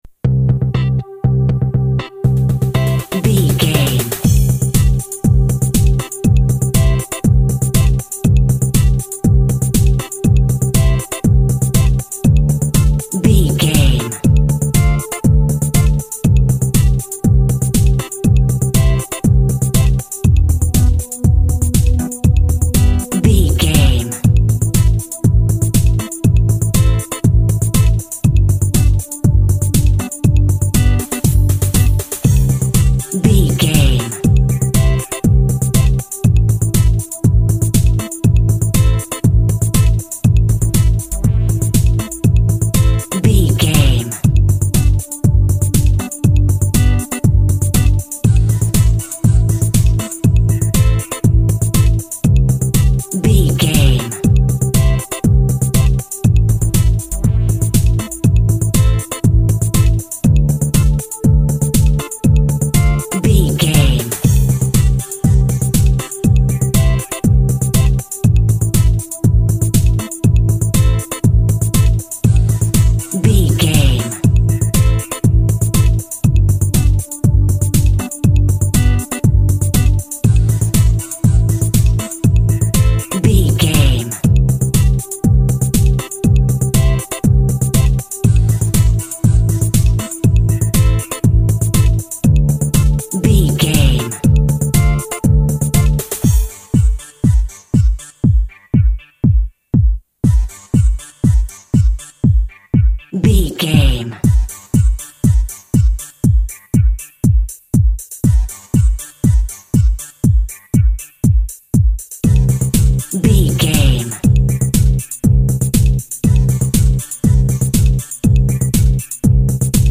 Ionian/Major
groovy
uplifting
futuristic
energetic
electric guitar
synthesiser
bass guitar
drums
techno
trance
synth lead
synth bass
electronic drums
Synth Pads